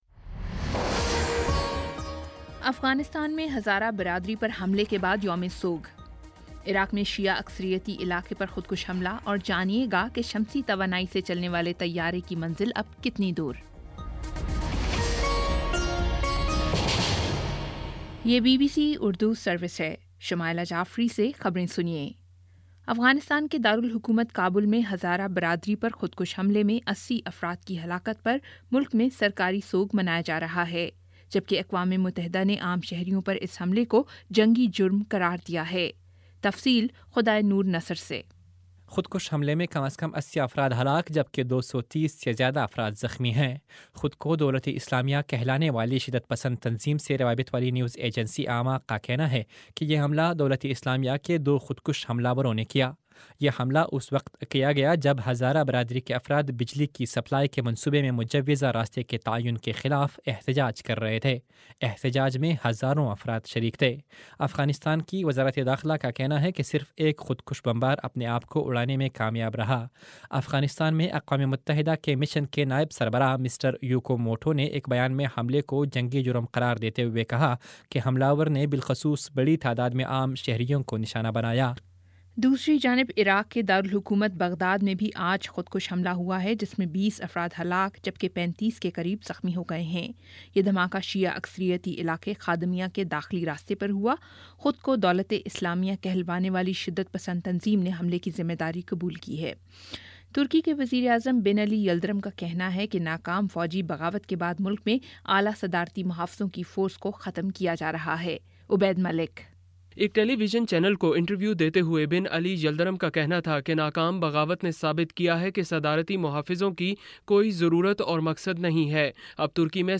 جولائی 24 : شام چھ بجے کا نیوز بُلیٹن